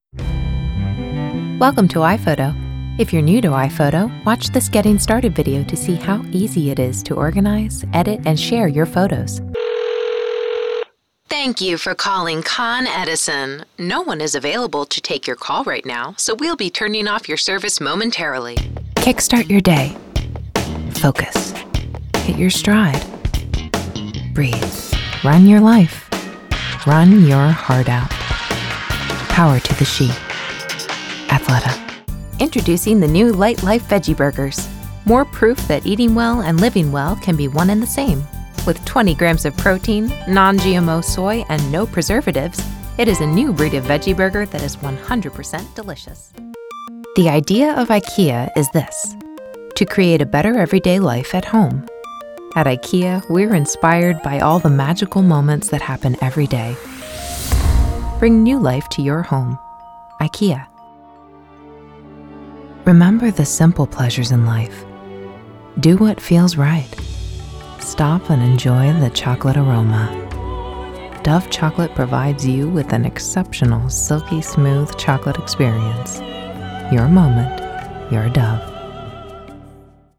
Female Voice Over, Dan Wachs Talent Agency.
Warm, Friendly, Conversational
Commercial